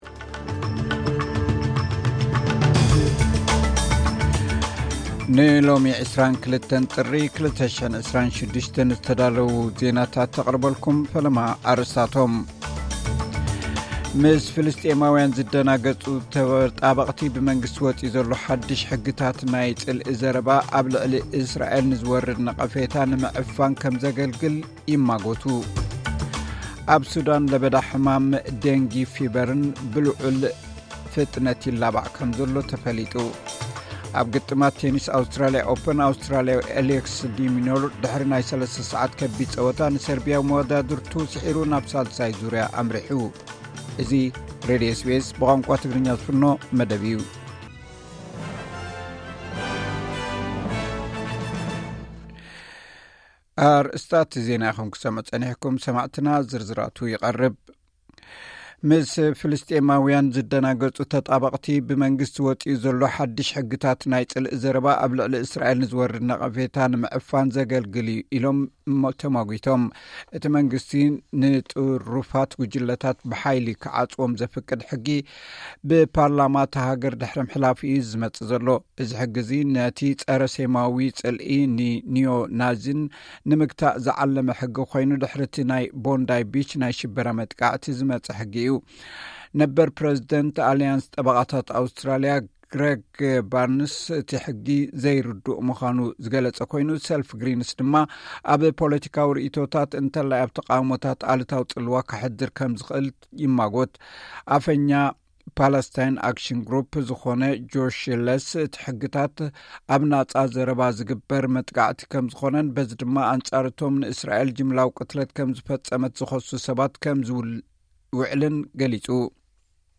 ዕለታዊ ዜና ኤስ ቢ ኤስ ትግርኛ (22 ጥሪ 2026)